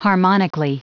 Prononciation du mot harmonically en anglais (fichier audio)